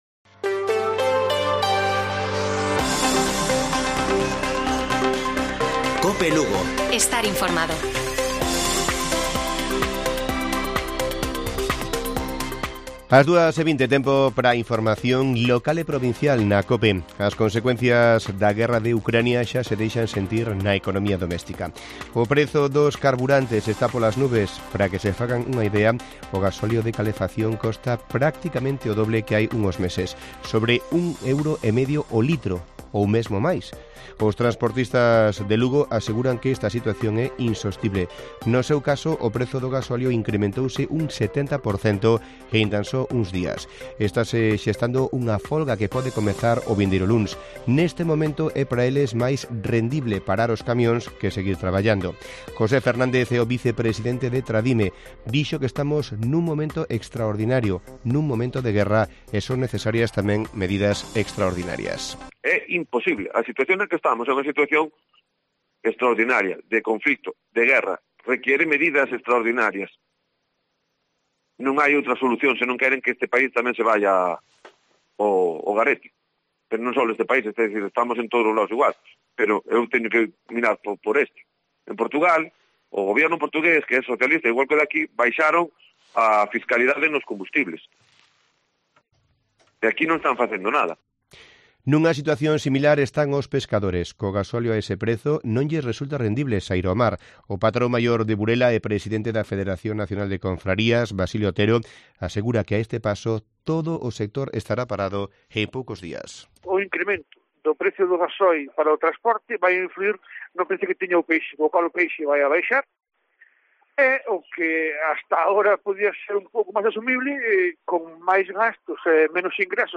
Informativo Mediodía de Cope Lugo. 09 de marzo. 14:20 horas